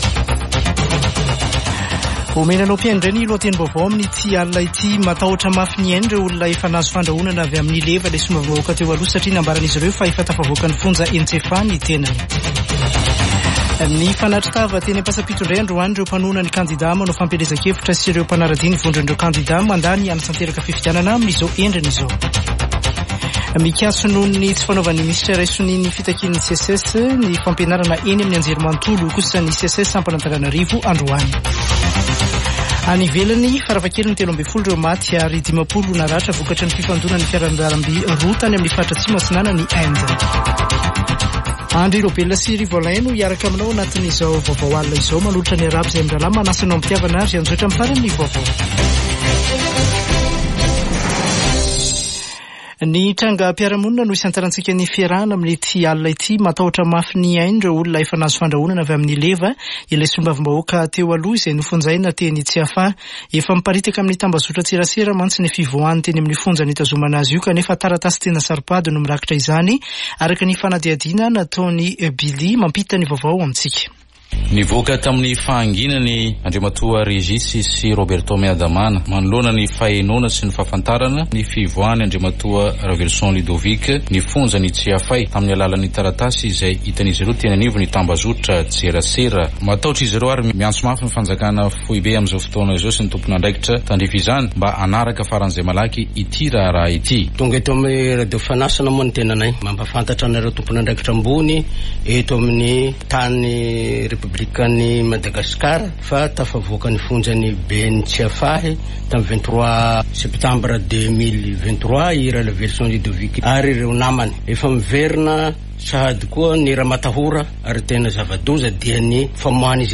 [Vaovao hariva] Alatsinainy 30 ôktôbra 2023